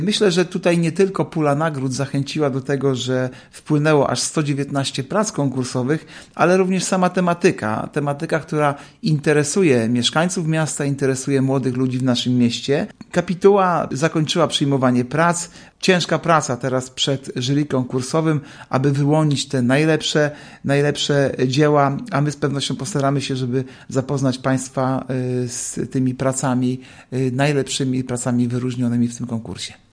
– Do wygrania jest 10 tysięcy złotych – mówi Artur Urbański, zastępca prezydenta Ełku.